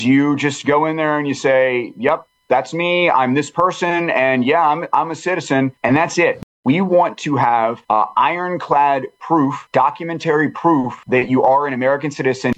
The caucus is looking at specifically voter fraud, saying it is far too easy for anyone to show up and vote with no accountability. Delegate Ryan Nawrocki says voter id would cut such vulnerabilities…